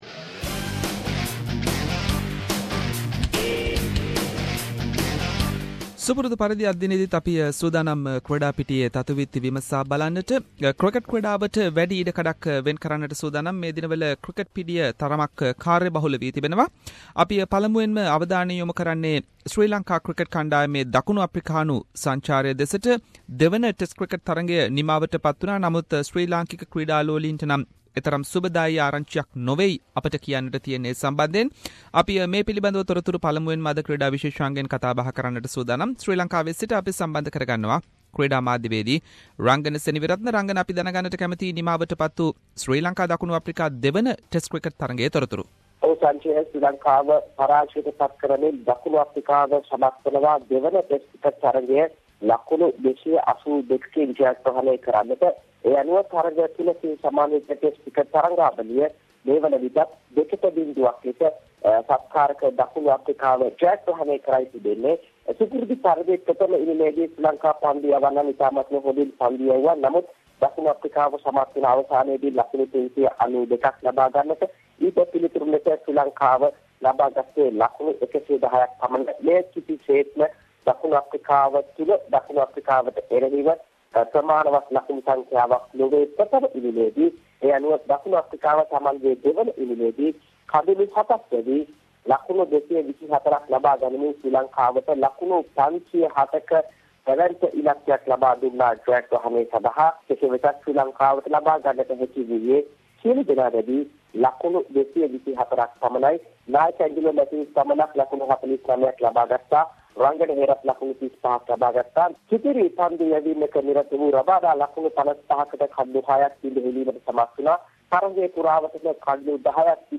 In this weeks SBS Sinhalese sports wrap…. Latest from Sri Lanka Cricket tour to South Africa, Big Bash league Latest and many more local and international sports news.